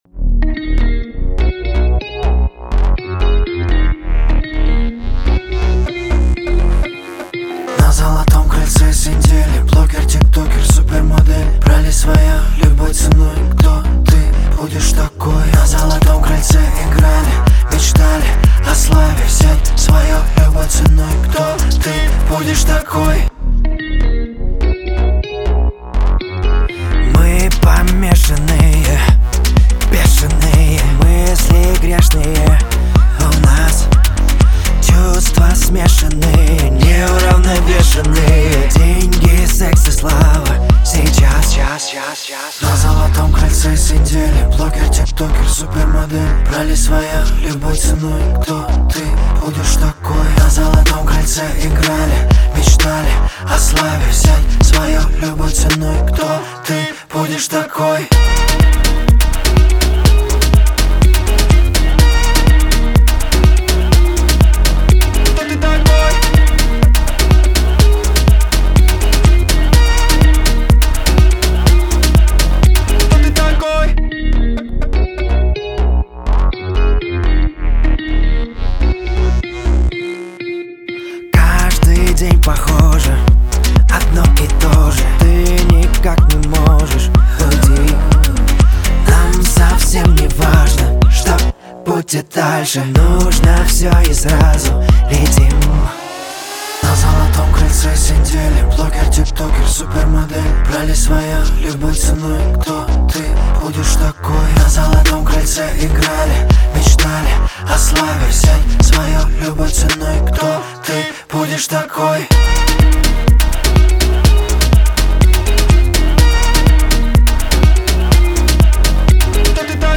поп-музыки с элементами R&B